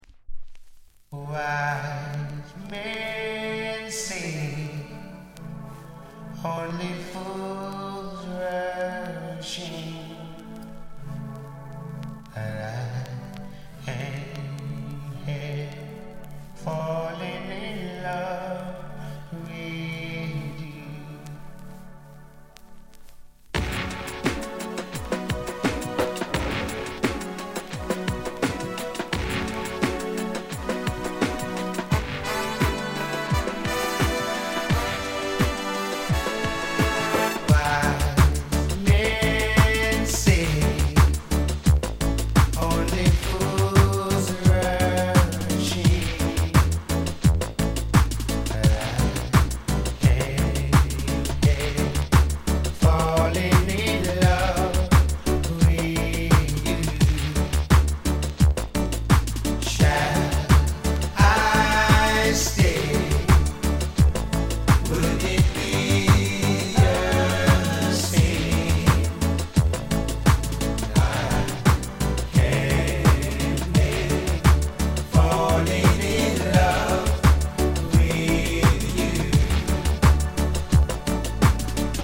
当前位置 > 首页 >音乐 >唱片 >世界音乐 >雷鬼